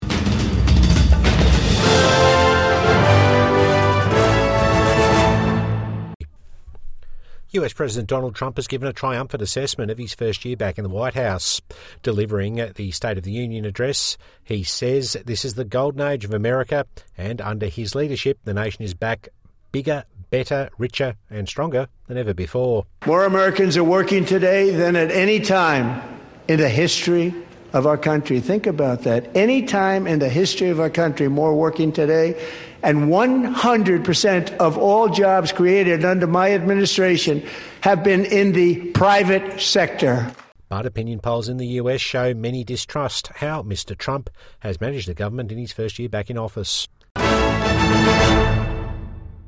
Trump delivers State of the Union address